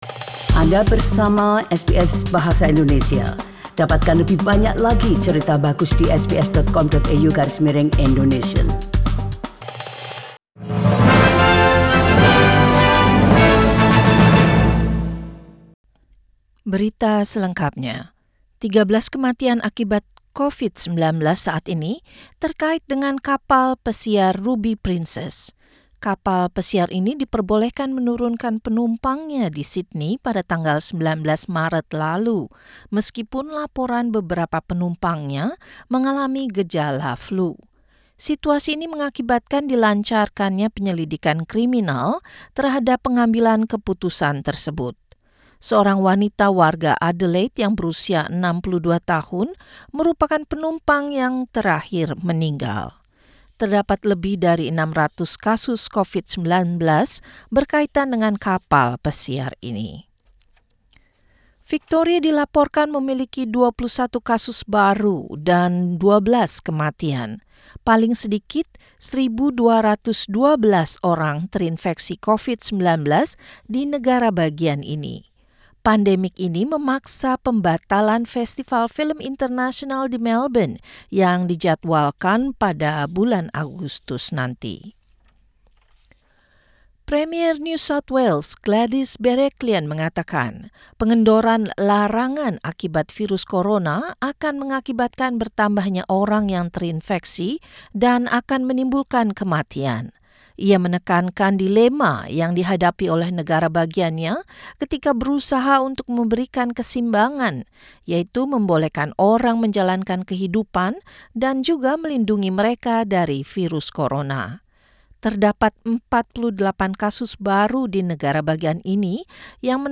SBS Radio News in Indonesian - 8 April 2020